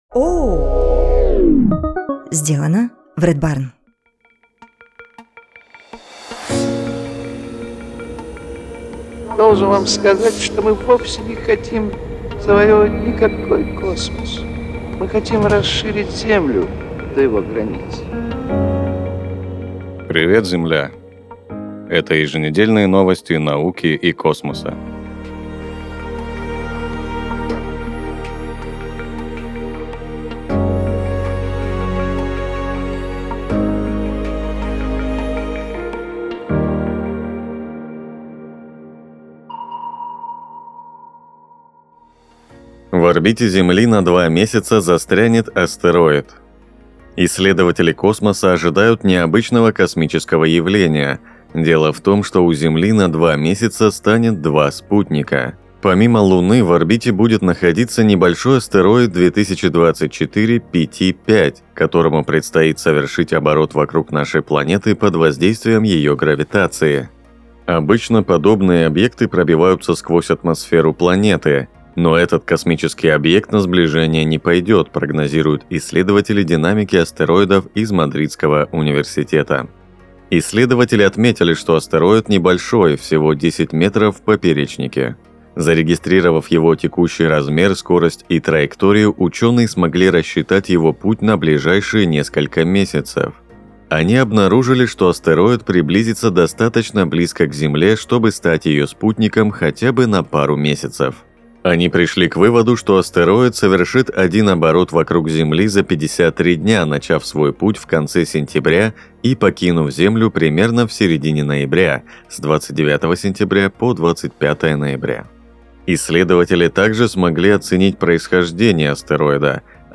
Ведет выпуск